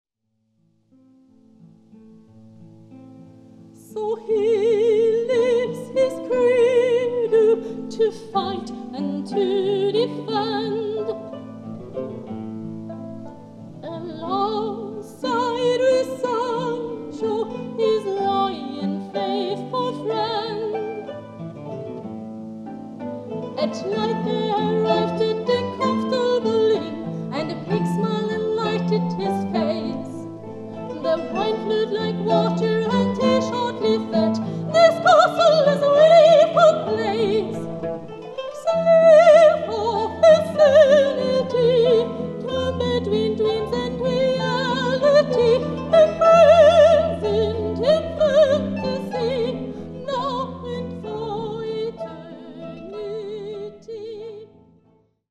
Sängerin, Performerin, Pädagogin
Erlebe Kreativität, Emotion und Inspiration, eine einfühlsame bis dramatische Stimme, schauspielerisches Können.
Ausschnitt aus der "Don Quixote Suite" von Christopher Kirsch, live 2018 Bruchsal, im Rahmen des Eurofestival Zupfmusik
Ausschnitt_Kirsch-Don-Quixote-Suite_-live_Mai_2018_im-Rahmen-Wettbewerb-Eurofestival-Zupfmusik_mit-LJZO-Sachsen.mp3